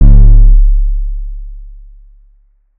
Waka KICK Edited (66).wav